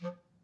DCClar_stac_F2_v1_rr2_sum.wav